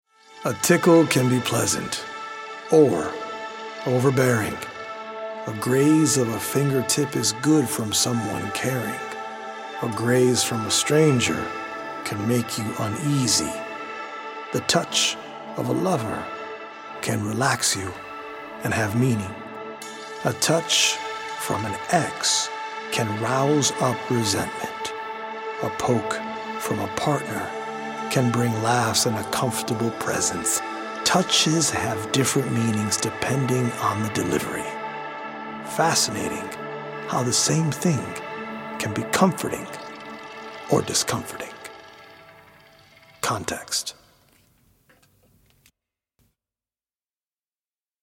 healing Solfeggio frequency music
EDM producer